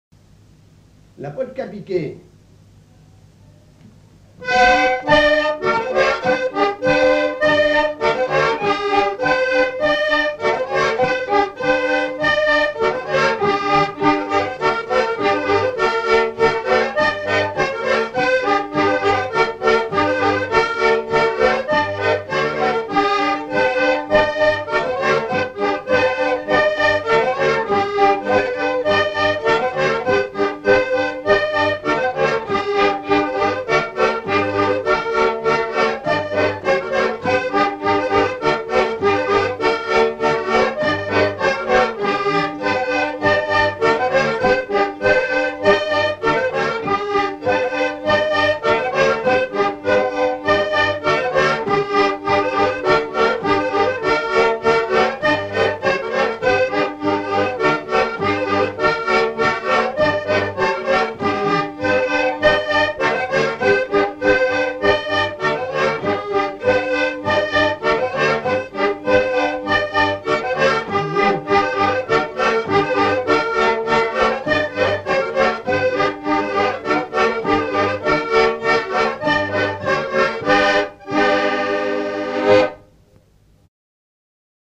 Rochetrejoux
danse : polka piquée
Pièce musicale inédite